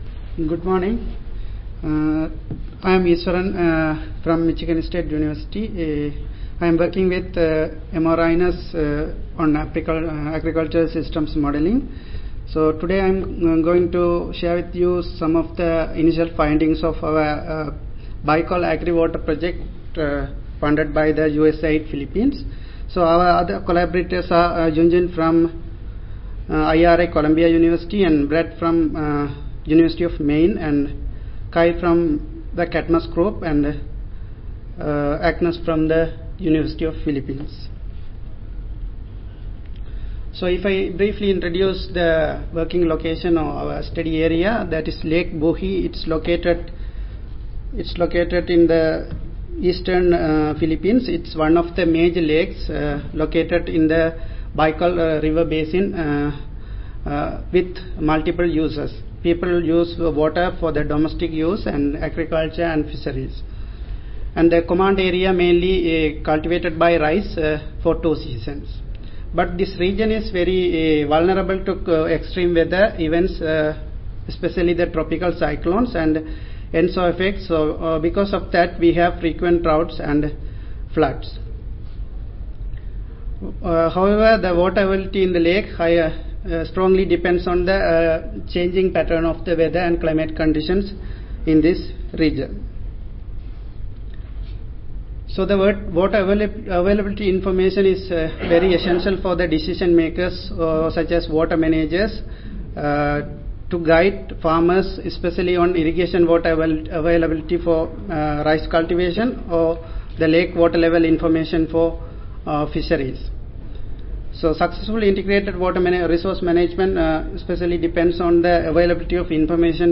See more from this Division: ASA Section: Climatology and Modeling See more from this Session: Climatology and Modeling General Oral I